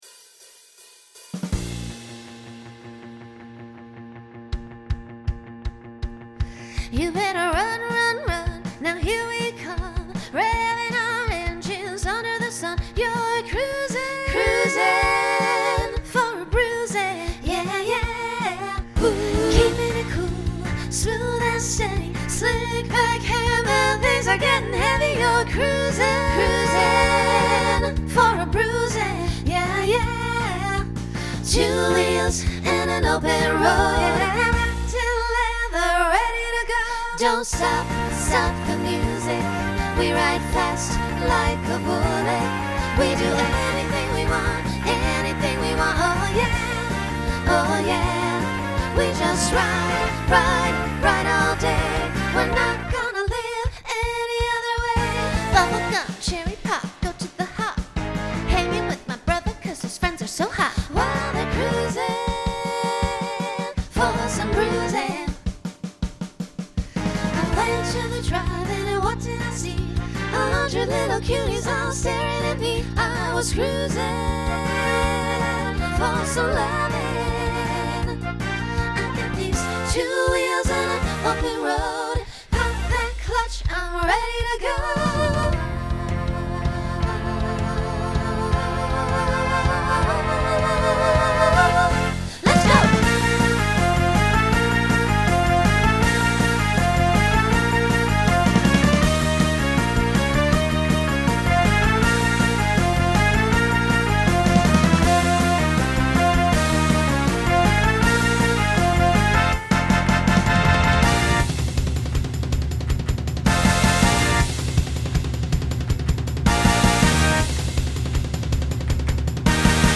Begins with optional quintet to facilitate a costume change.
Broadway/Film , Rock
Transition Voicing SSA